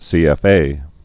(sēĕf-ā)